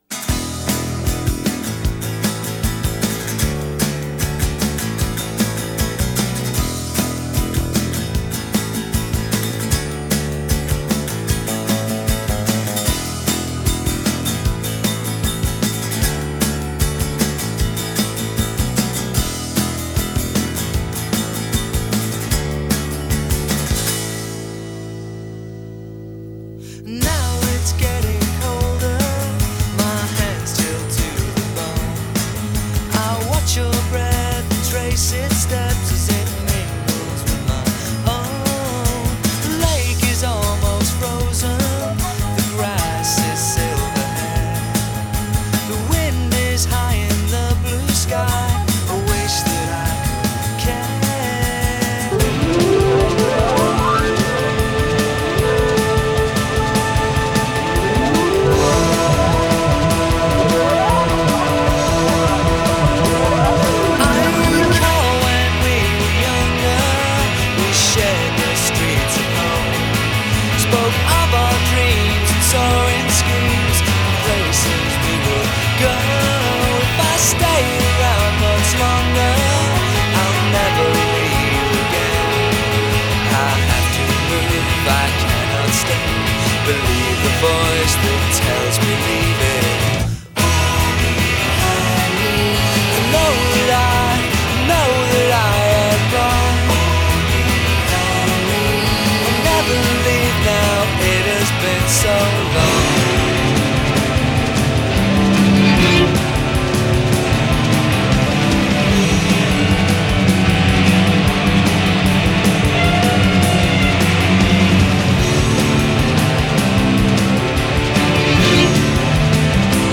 Groupe de rock anglais originaire de Liverpool.